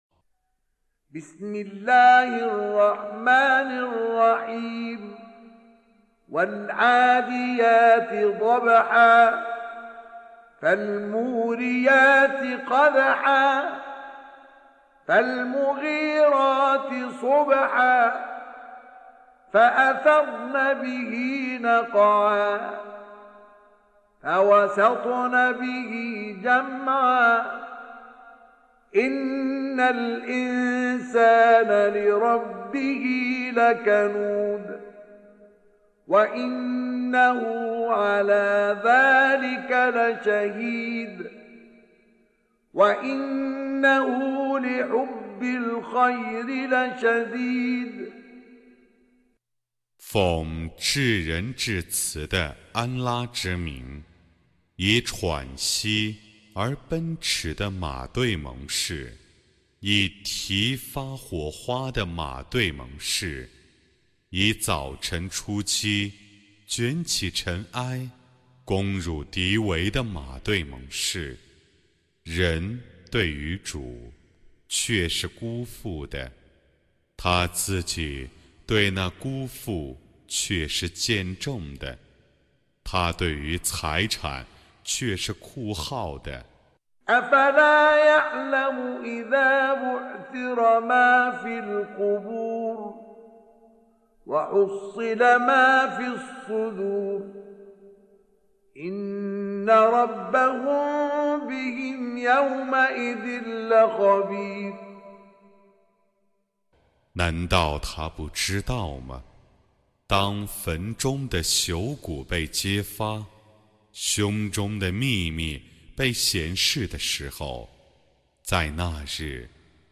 Surah Repeating تكرار السورة Download Surah حمّل السورة Reciting Mutarjamah Translation Audio for 100. Surah Al-'Adiy�t سورة العاديات N.B *Surah Includes Al-Basmalah Reciters Sequents تتابع التلاوات Reciters Repeats تكرار التلاوات